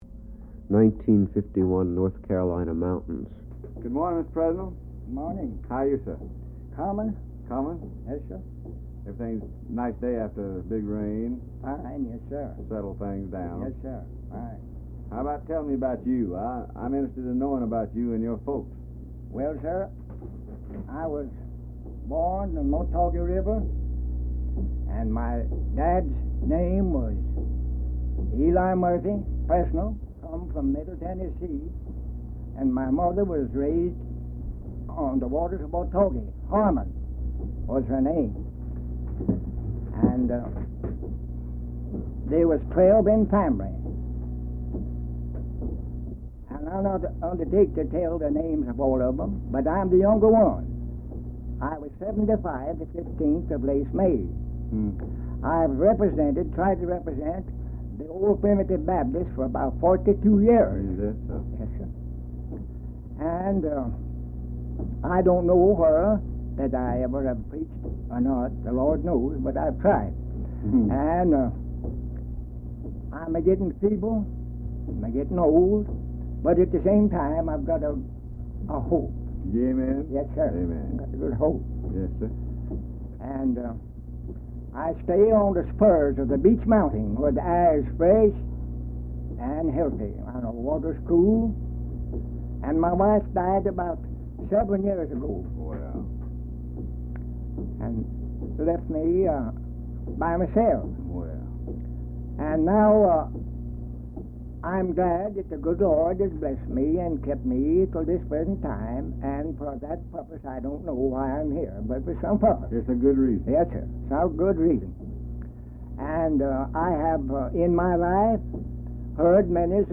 (2:24 - 3:33) Sings "The Ballad of George Collins"